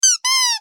squeak.mp3